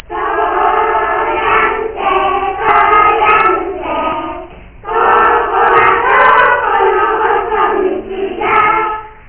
この天神様は、わらべ唄「とおりゃんせ」発祥の地といわれています。